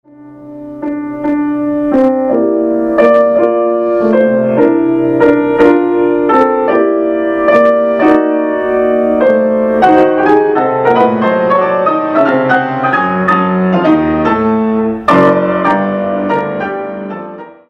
Pianist
F. Chopin: Ballade in F Major, Op. 38 - mm. 130-137